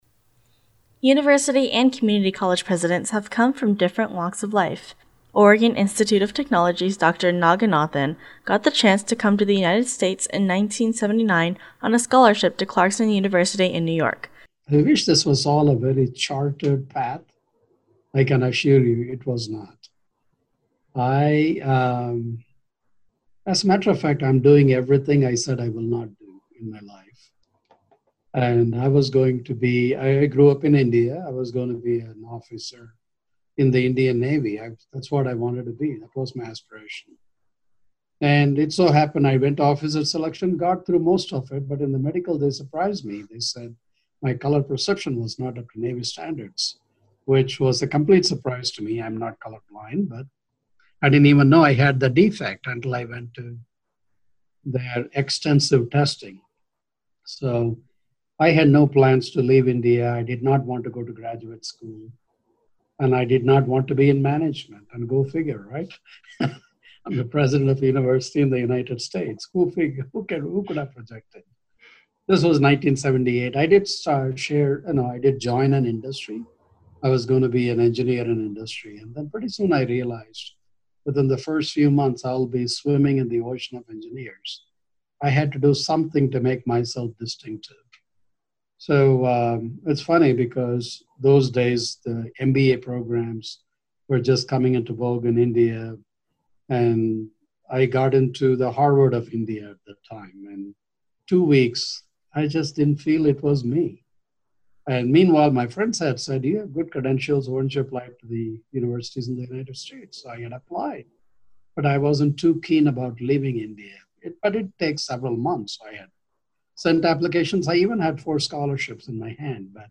Journeys into Educational Leadership – Audio Documentary